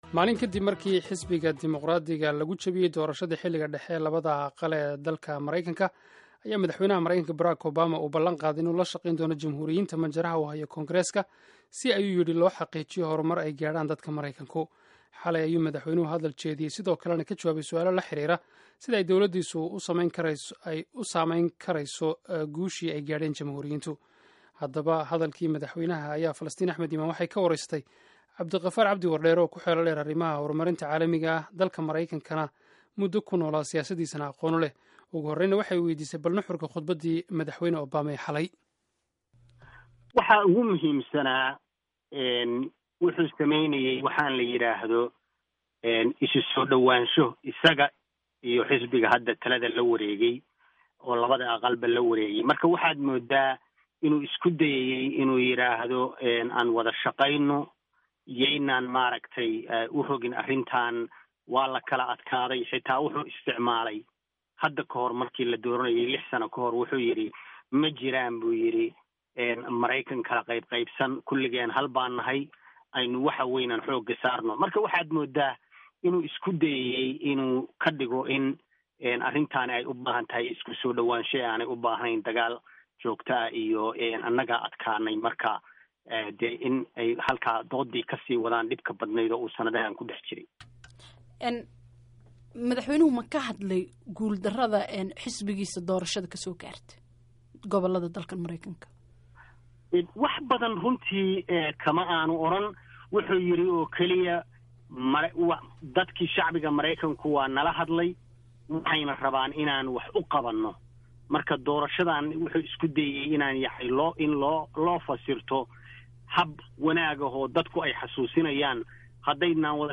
Falanqeyn: Khudbaddii Obama